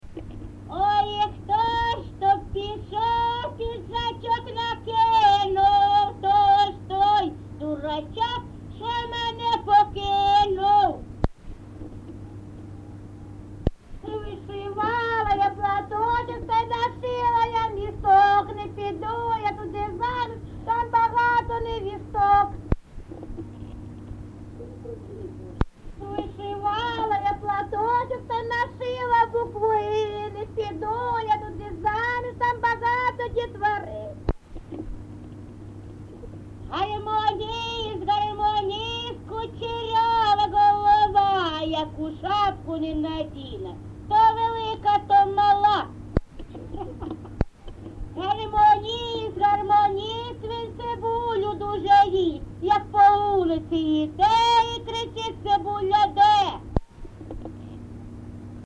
ЖанрТриндички
Місце записус. Гнилиця, Сумський район, Сумська обл., Україна, Слобожанщина